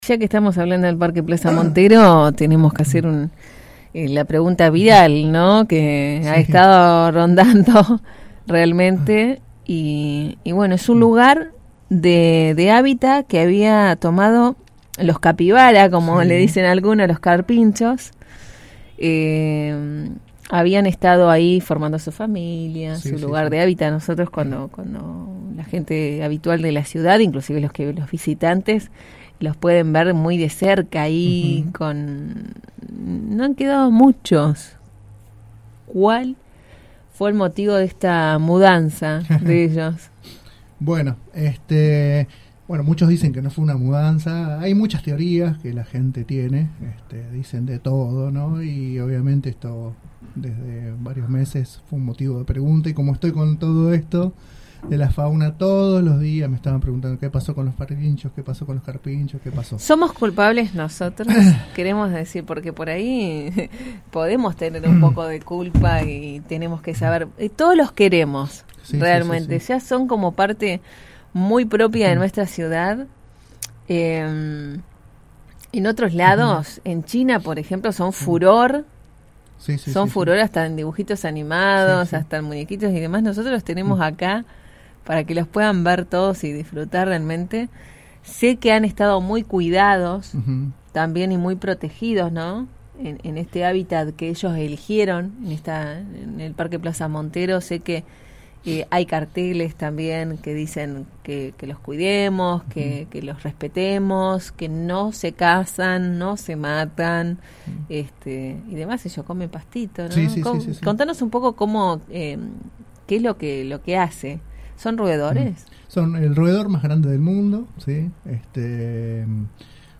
En otro momento de la entrevista, dijo que «soy optimista y pienso que algún momento regresarán en cantidad».